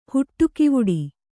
♪ huṭṭu kivuḍi